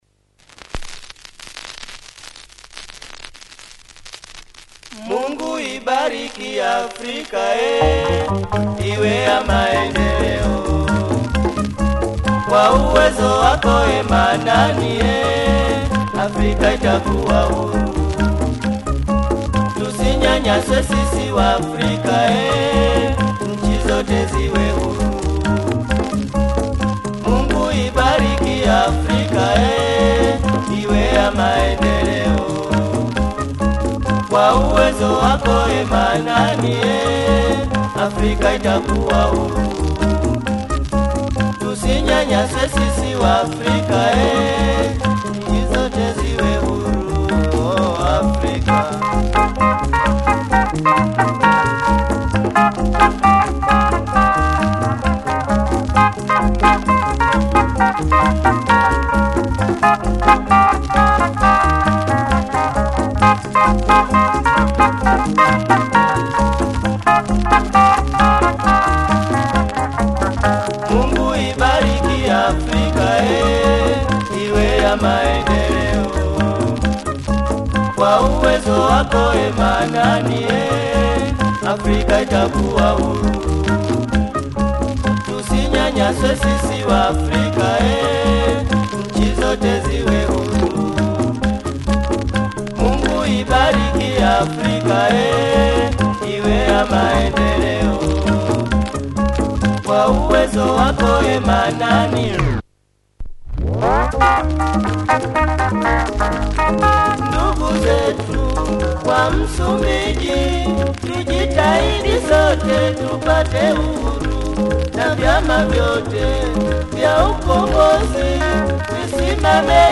Fantastic horn section and sax